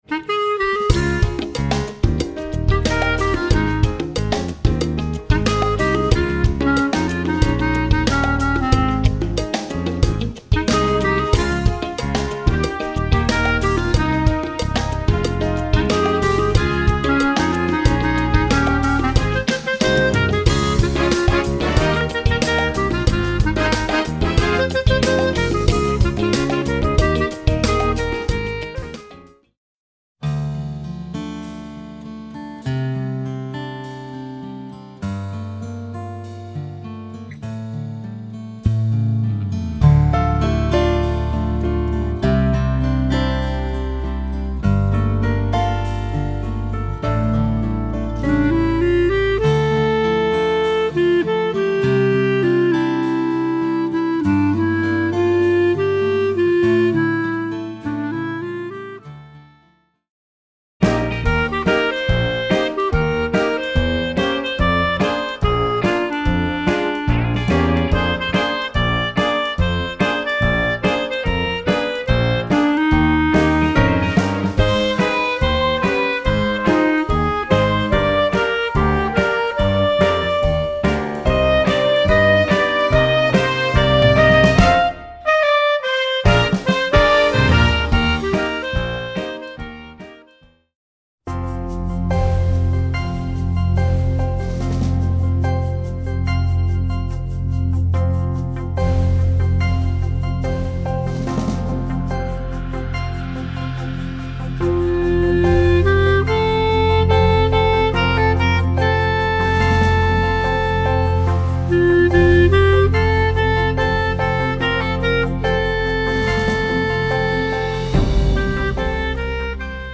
Voicing: Clarinet Collection